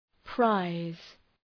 {praız}